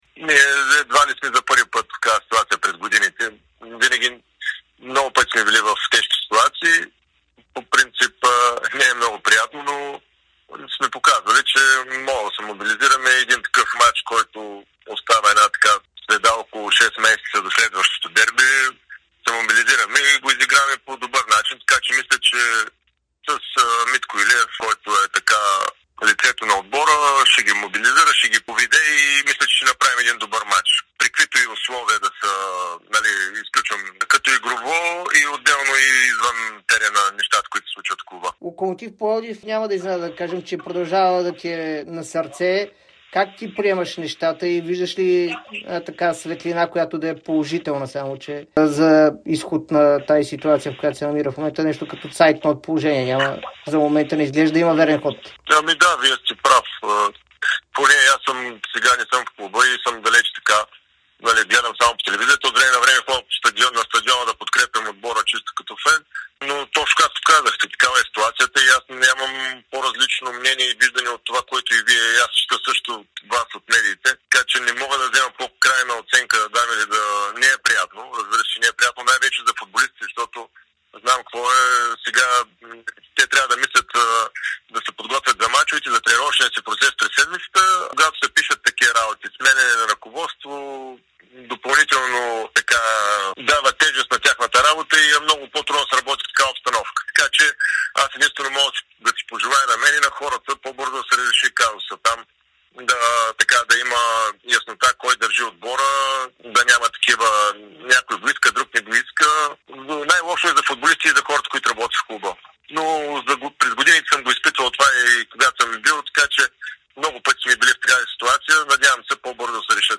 Бившият капитан на Локомотив Пловдив Мартин Камбуров говори пред Дарик и dsport за ситуацията в клуба и сподели очакванията си за дербито с Ботев.